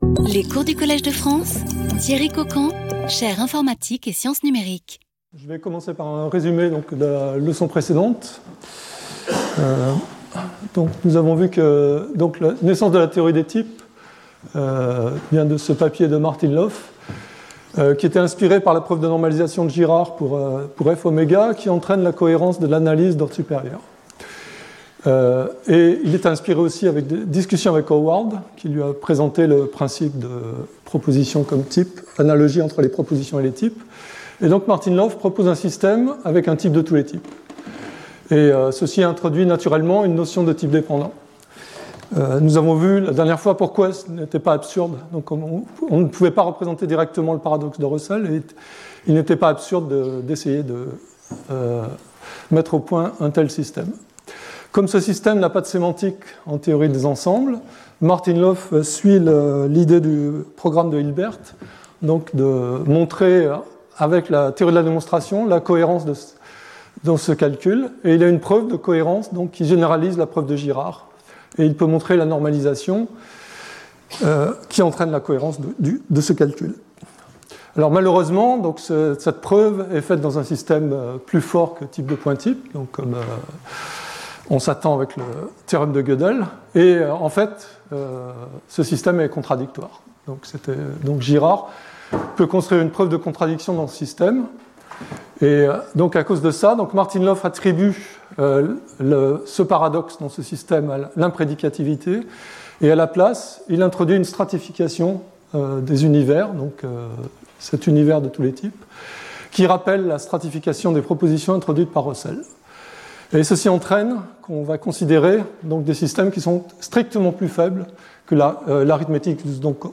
Skip youtube video player Listen to audio Download audio Audio recording Lecture outline : Curry-Howard ; gentzen's natural deduction ; inductive definitions following Martin-Löf ; algebraic presentation of type theory and term model as initial model ; some examples of models, in particular the set model and prefix models.